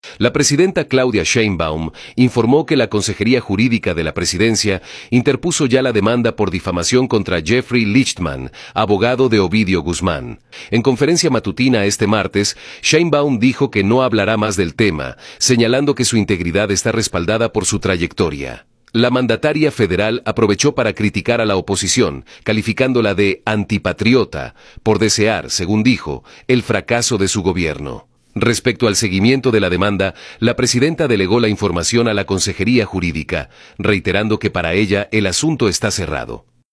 La presidenta Claudia Sheinbaum informó que la Consejería Jurídica de la Presidencia interpuso ya la demanda por difamación contra Jeffrey Lichtman, abogado de Ovidio Guzmán. En conferencia matutina este martes, Sheinbaum dijo que no hablará más del tema, señalando que su integridad está respaldada por su trayectoria. La mandataria federal aprovechó para criticar a la oposición, calificándola de “antipatriota” por desear, según dijo, el fracaso de su gobierno.